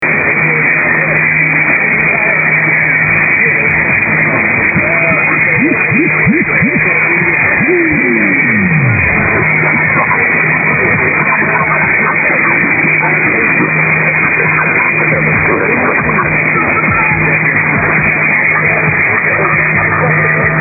UNID SWEEPER AT 0300UTC ON 1540:
There is a very distinctive sweeper being used at the top of the hour.